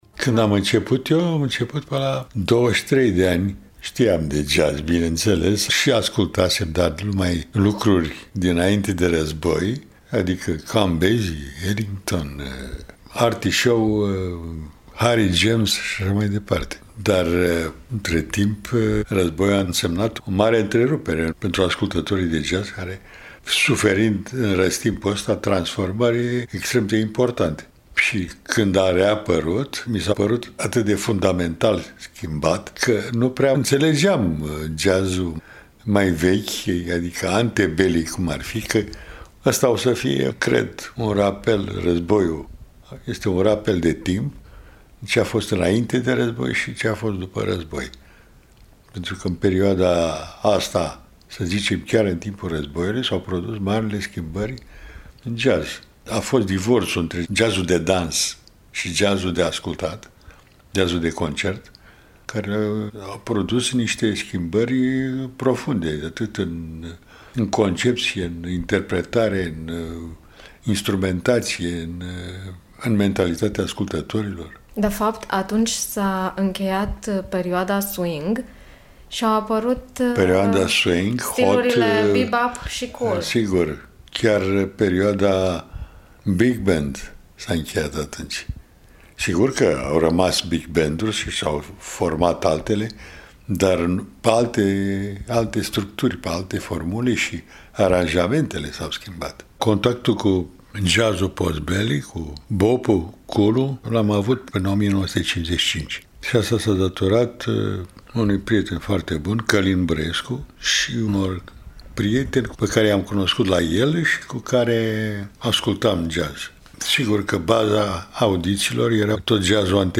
Colecția de Jazz la Radio Târgu-Mureș: interviu cu pianistul și compozitorul Marius Popp
CDJ-Marius-Popp-fragm-interviu1.mp3